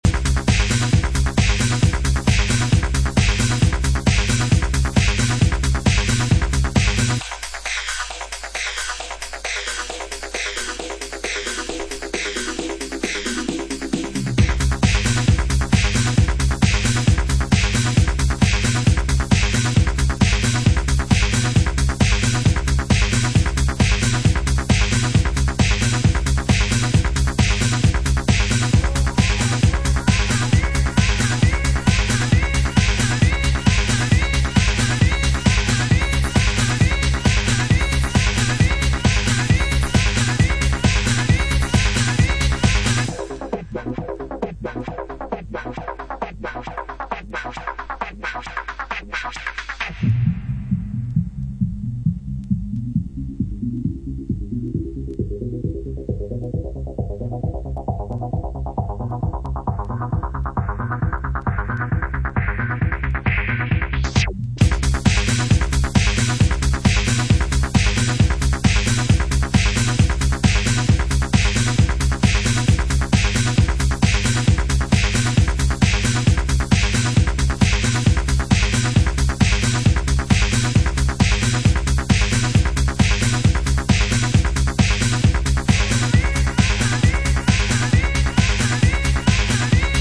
peak-time techno cuts
Techno